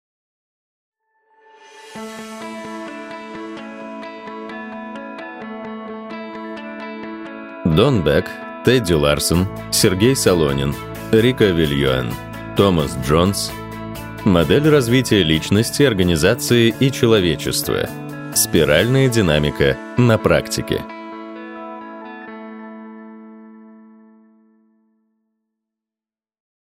Аудиокнига Спиральная динамика на практике. Модель развития личности, организации и человечества | Библиотека аудиокниг